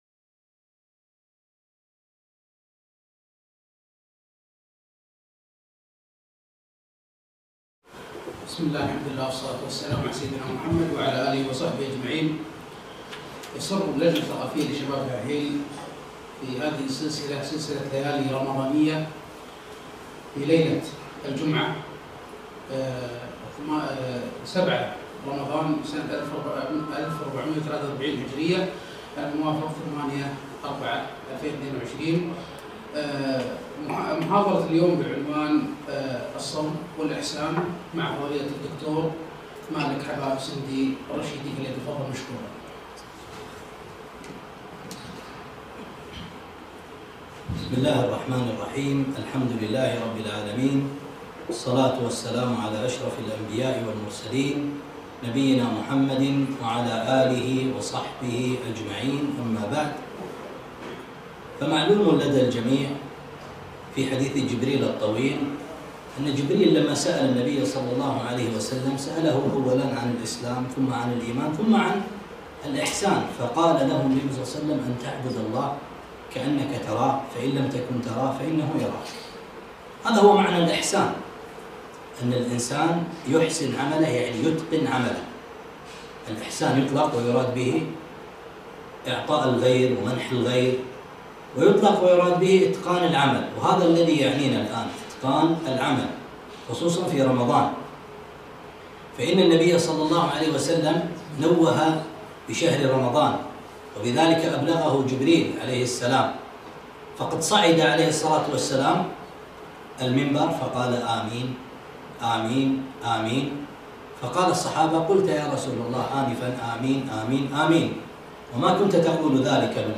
محاضرة - الصوم و الإحسان